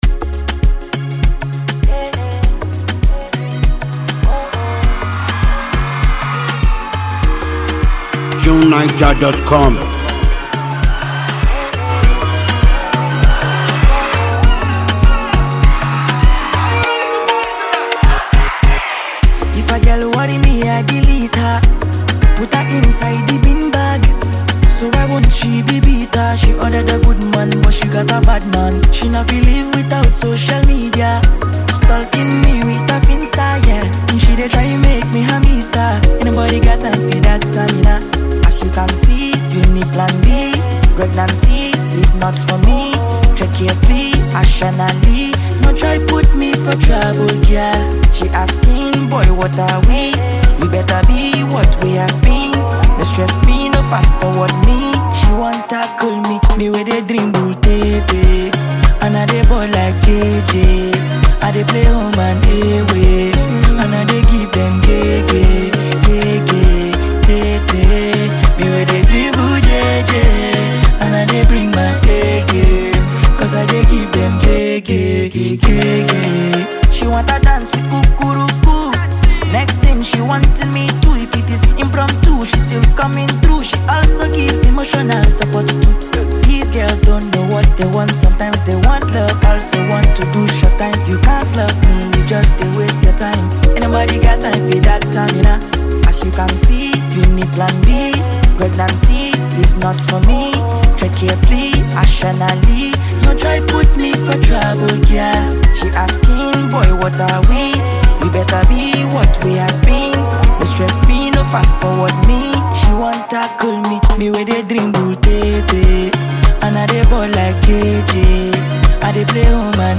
chilling tune